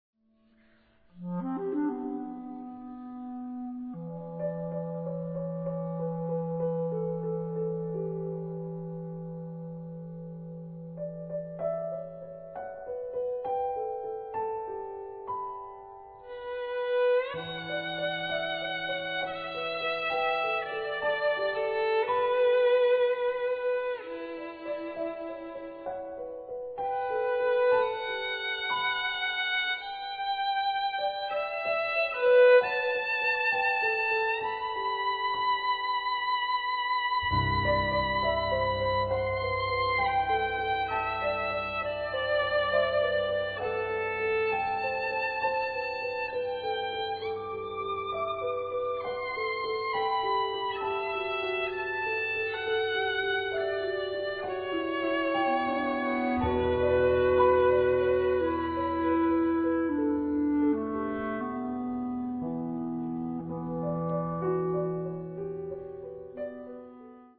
A trio for clarinet, violin, and piano.